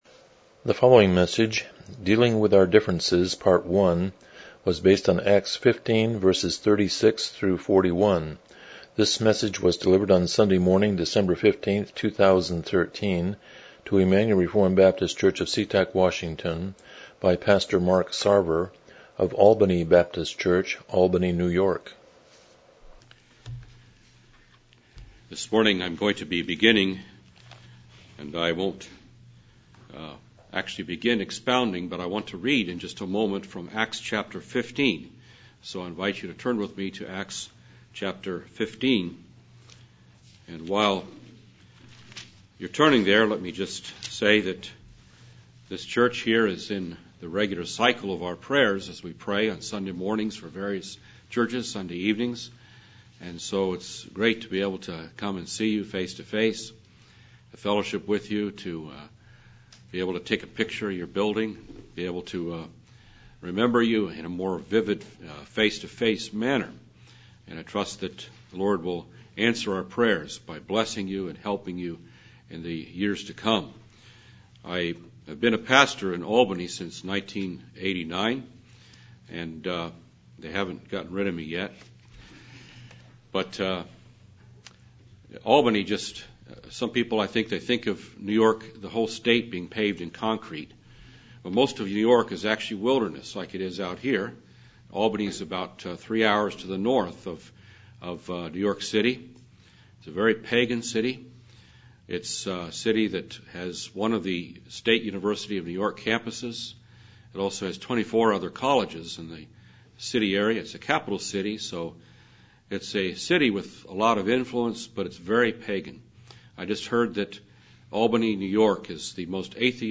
Miscellaneous Passage: Acts 15:36-41 Service Type: Morning Worship « The Generous Landowner 23 The Sovereignty of God